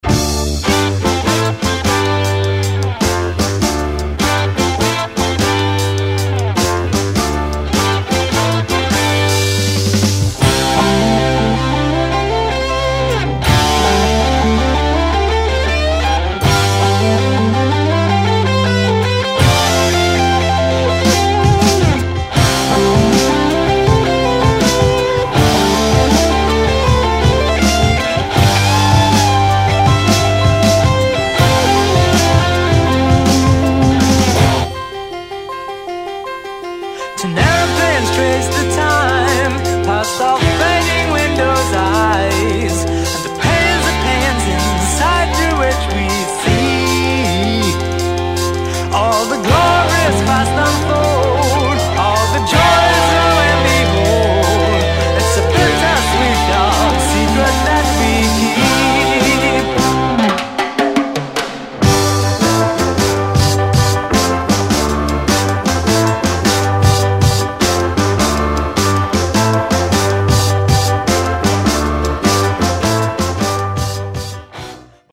ホーム ROCK LP B